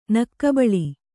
♪ nakkabaḷi